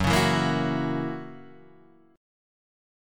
Gb7 chord